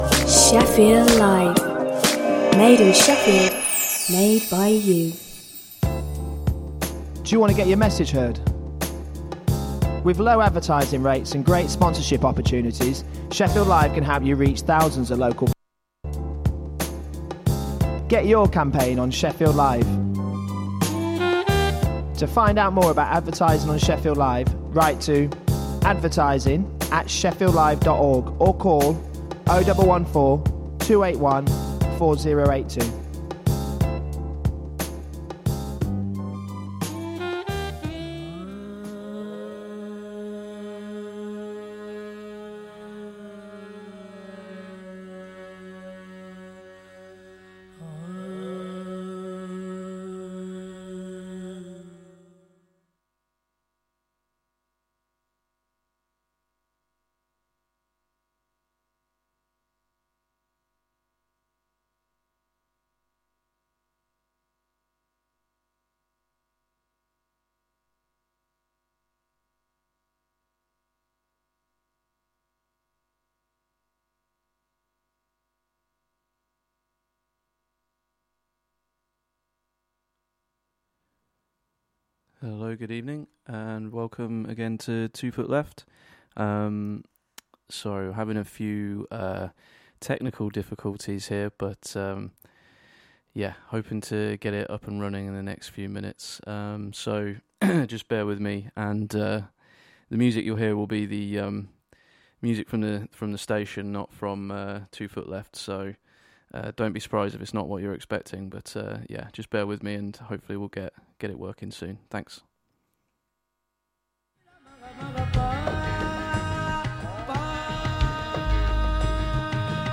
Underground, local, global, universal music and people.